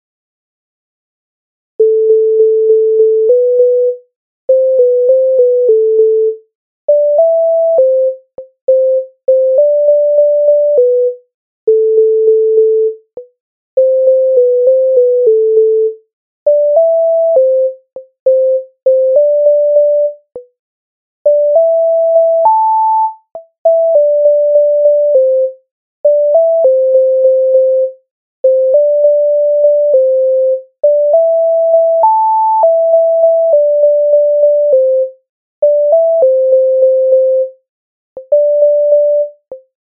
MIDI файл завантажено в тональності C-dur